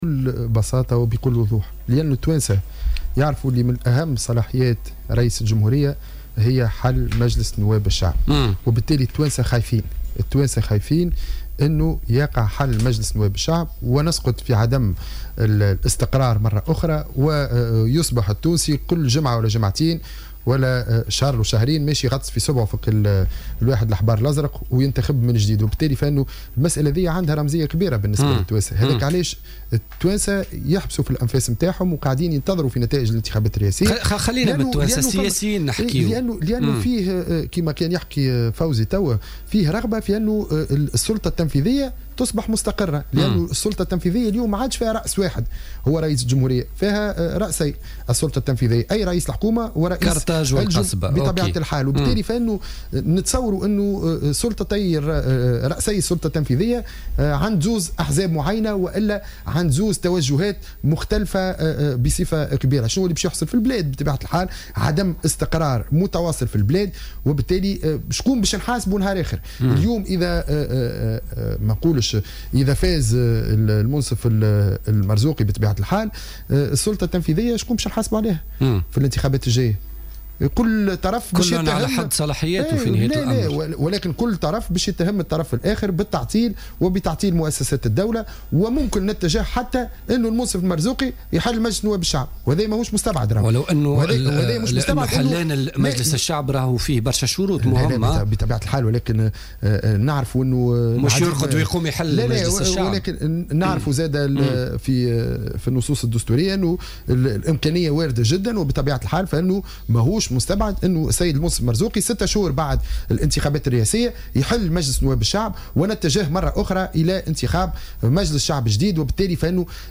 قال النائب السابق بالمجلس الوطني التأسيسي،محمود البارودي ضيف برنامج "بوليتيكا" اليوم الثلاثاء إنه من غير المستبعد أن يقوم المرشح للانتخابات الرئاسية،محمد المنصف المرزوقي بحل مجلس نواب الشعب في صورة فوزه في الانتخابات الرئاسية.